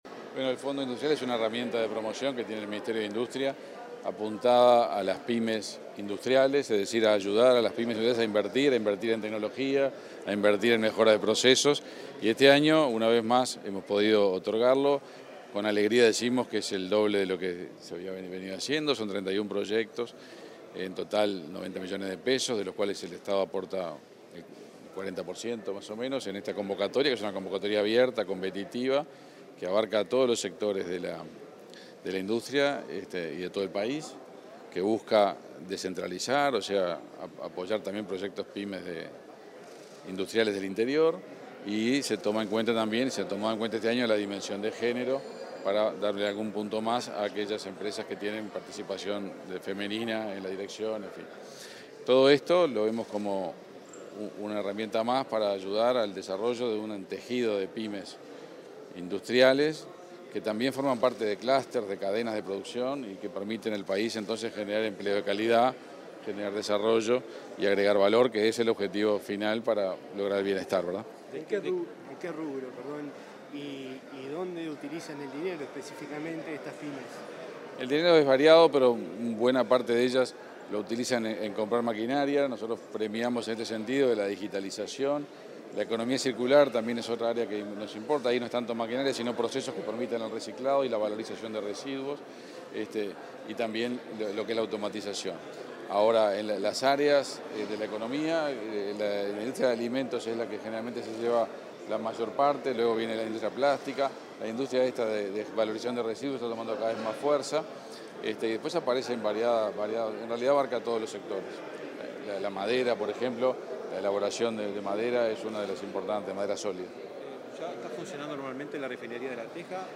Declaraciones de prensa del ministro Omar Paganini
El ministro de Industria, Omar Paganini, dialogó con la prensa luego de participar del acto de entrega de de certificados a proyectos seleccionados